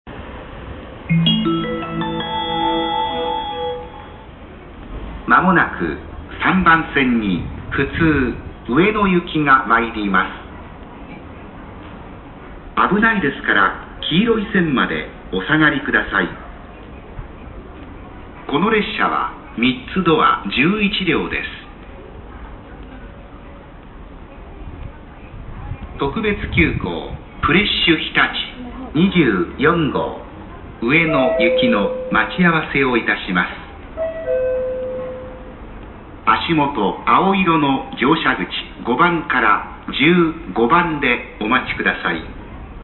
接近放送普通上野行き3ドア11両普通上野行き3ドア11両の接近放送です。
退避を行う案内をしますが、少し今までとは少し違う言い回しの部分があります。